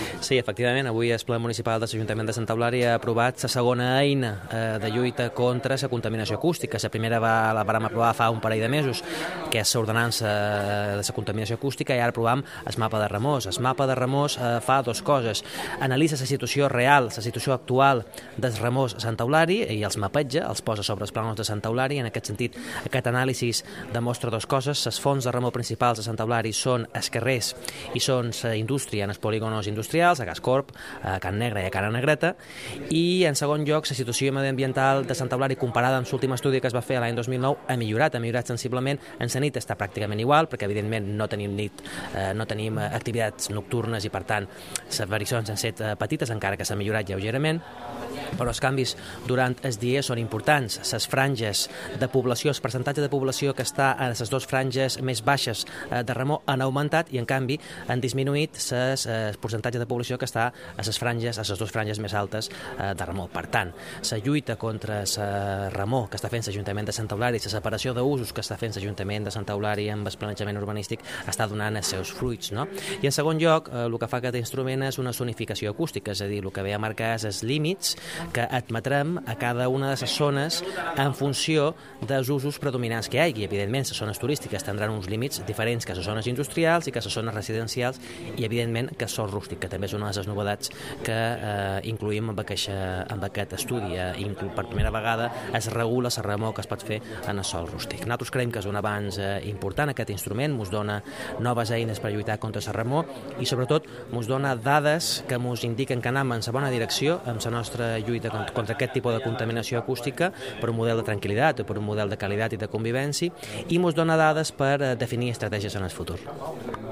Corte de voz del concejal Mariano Juan-Mapa Ruidos